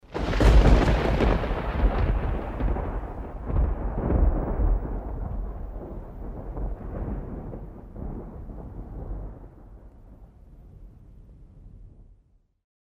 thunder1.mp3